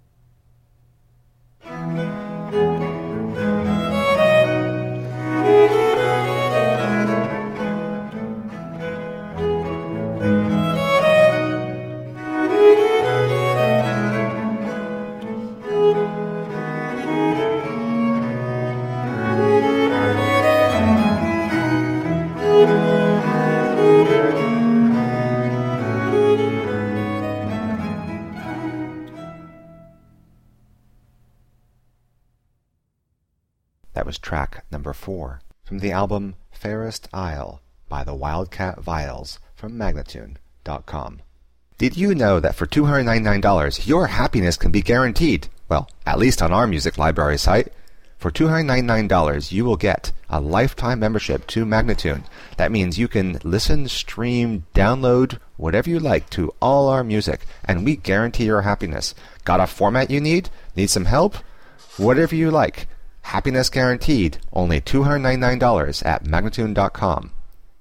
Viola da gamba girls gone wild!!.